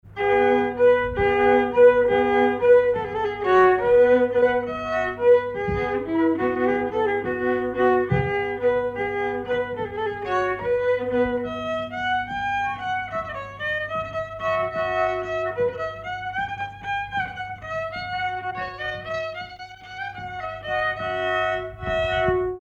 Valse lente
danse : valse
Pièce musicale inédite